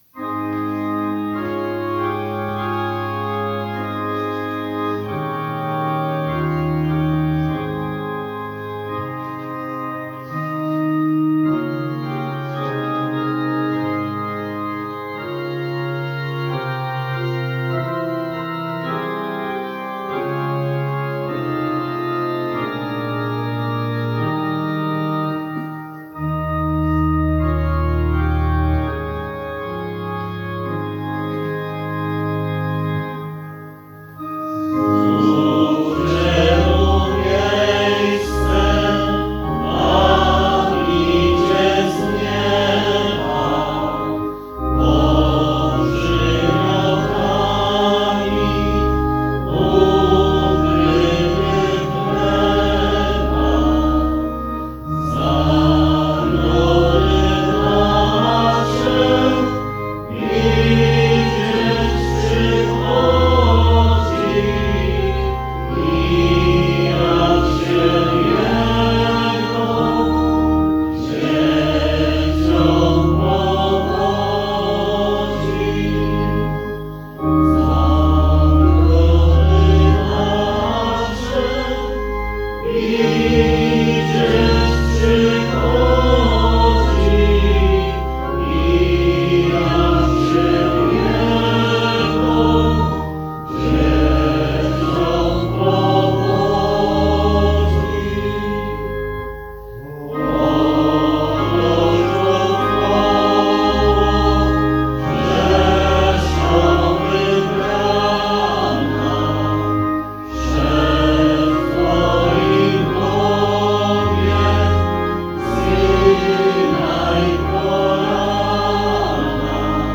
Śpiewaliśmy Panu Jezusowi w oratorium.
Organy